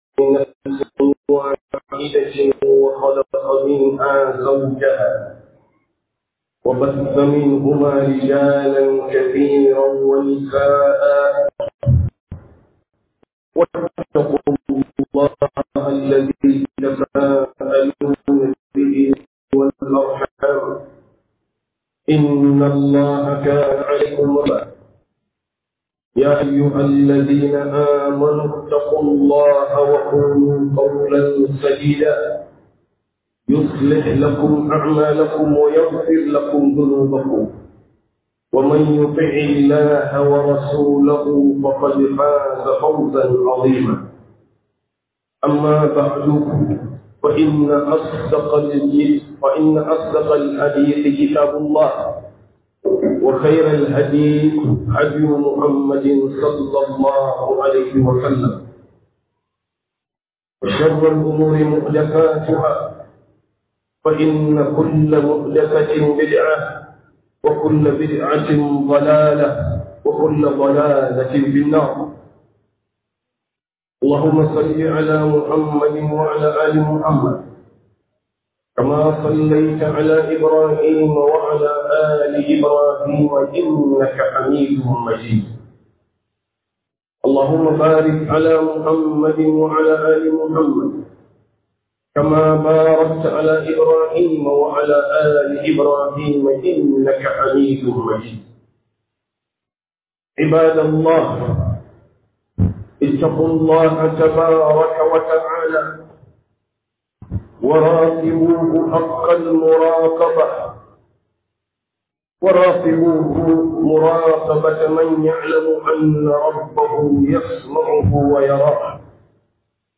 - Huduba